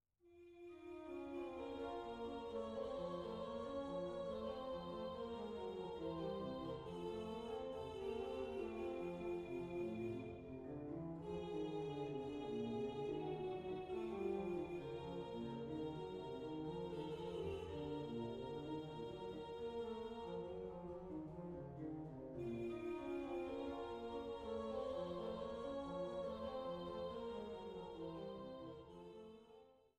Schlosskirche Altenburg
Cembalo